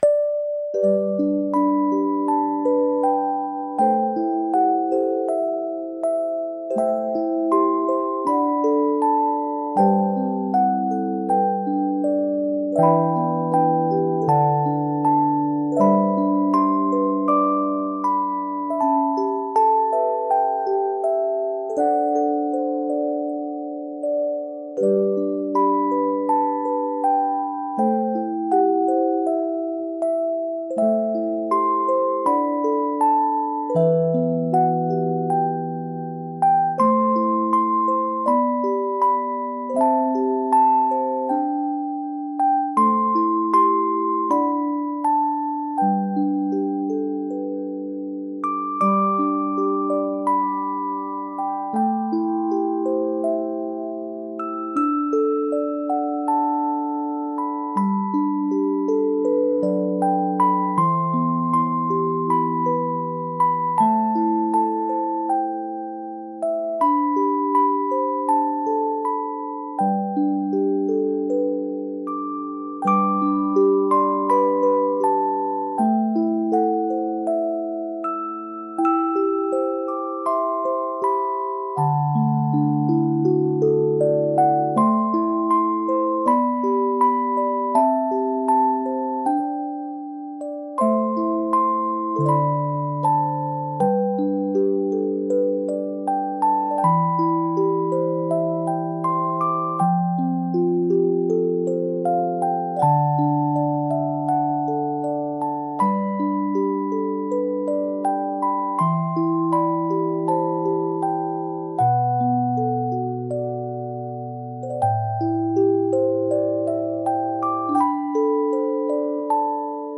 Музыка для сна , Детские колыбельные